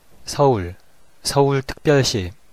Seol (prononciat: /seˈuɫ/; en corean: 서울; en transcripcion actuala: Seoul, /shʌul/
Ko-Seoul.ogg.mp3